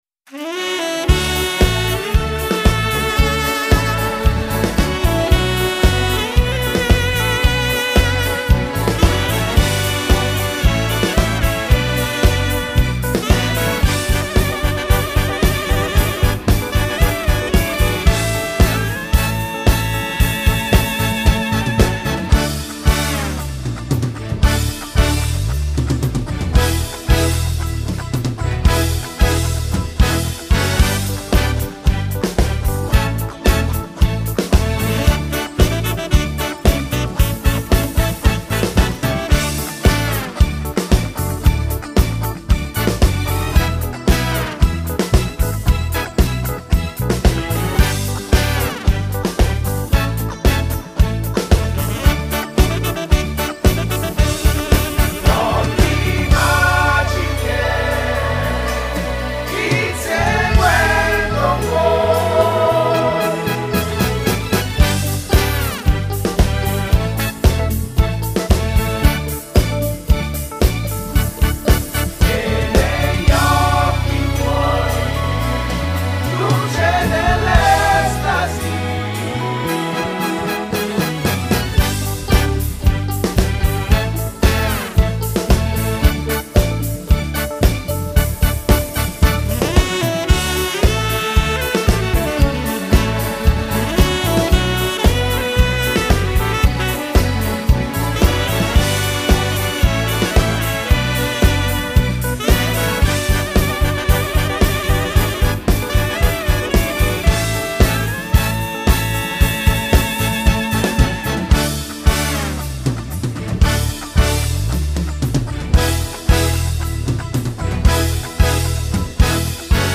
strumental